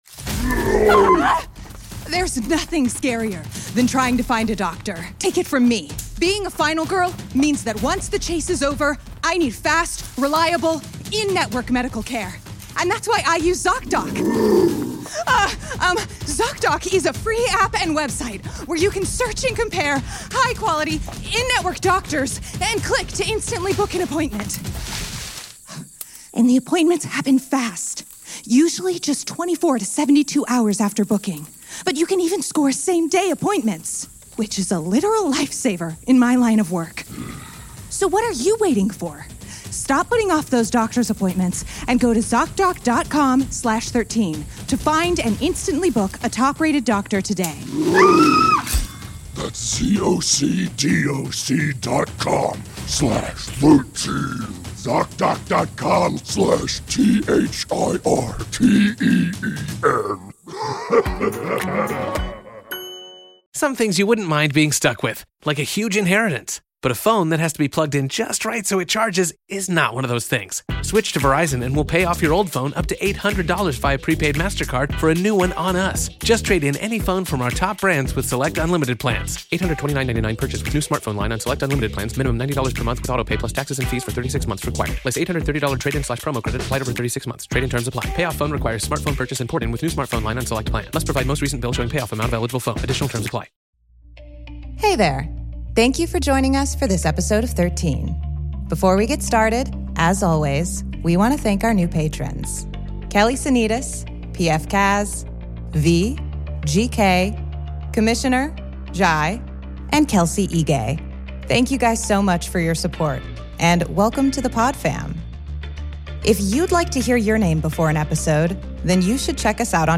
Narrated
Editing and sound design